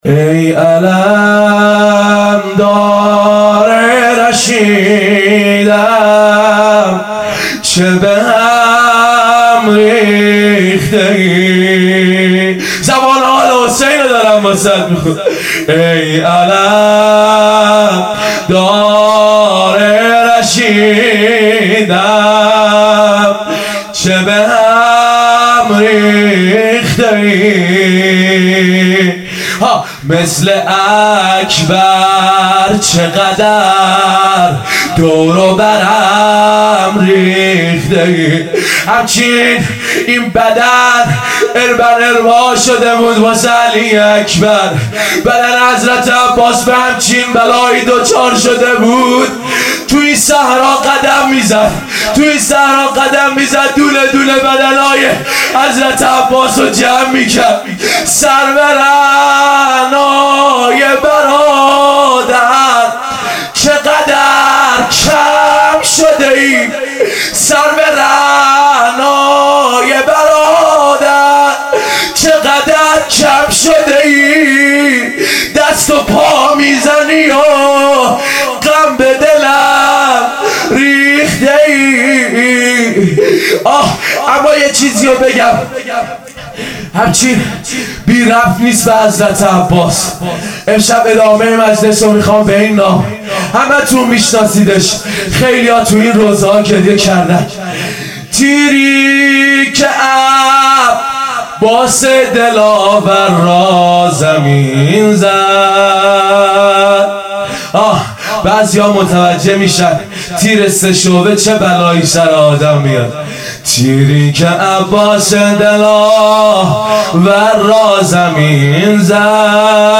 روضه
شب اول | مسجد امام موسی بن جعفر علیه السلام | صفر 1440 |هیئت مکتب الرضا علیه السلام | دبیرستان امام سجاد علیه السلام